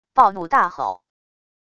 暴怒大吼wav音频